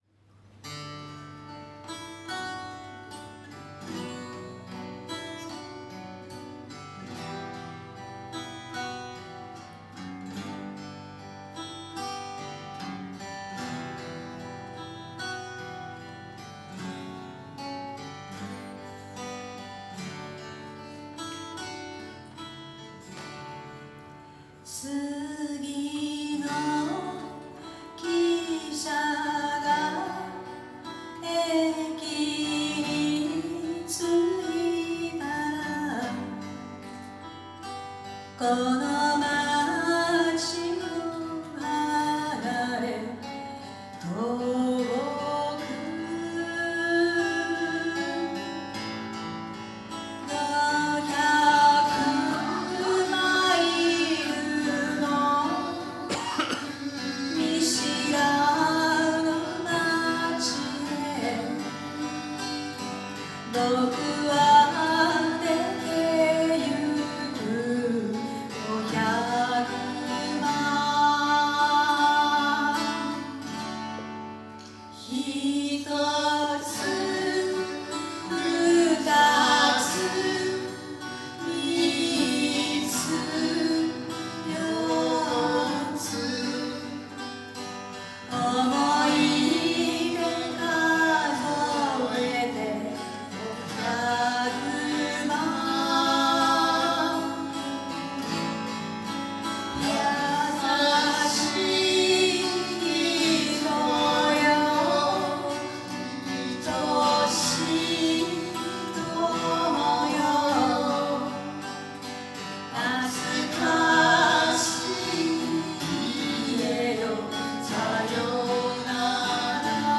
ライブ等の活動記録です。